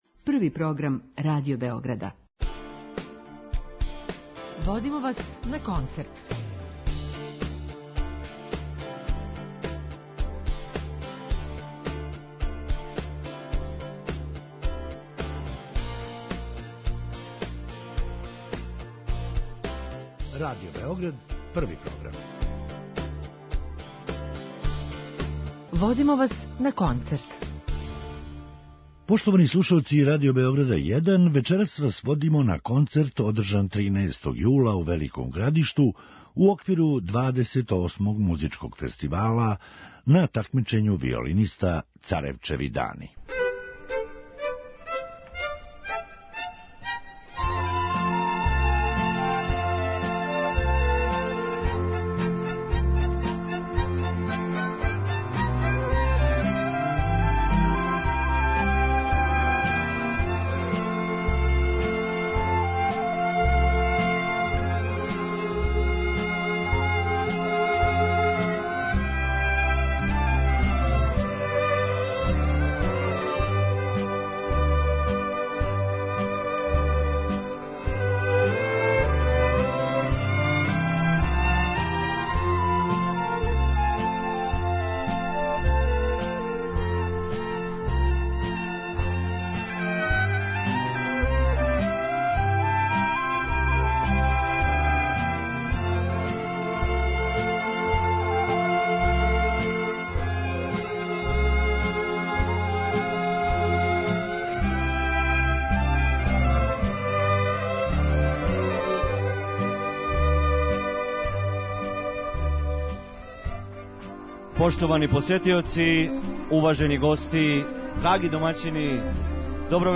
Емитујемо снимак такмичарске вечери двадесет осмог музичког фестивала Царевчеви дани.